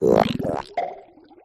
cry